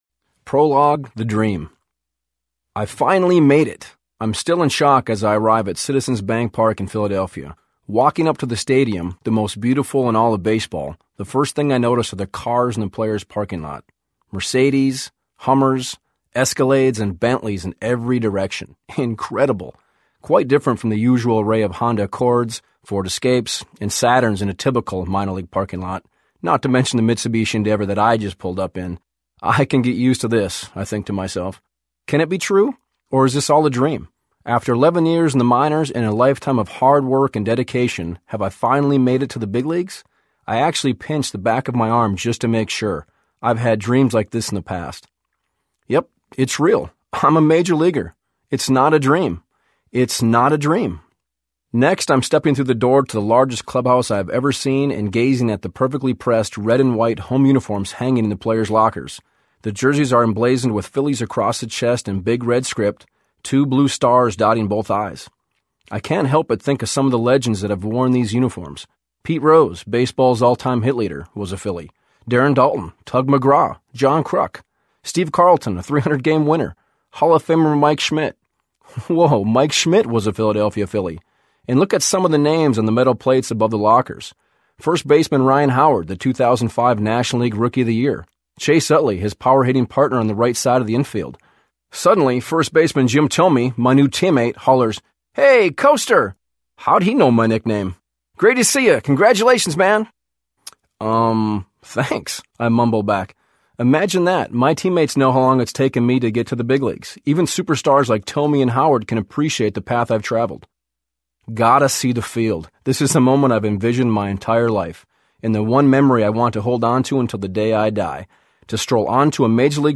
The 33-Year-Old Rookie Audiobook
Narrator